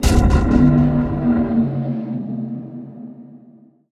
PixelPerfectionCE/assets/minecraft/sounds/mob/enderdragon/hit2.ogg at mc116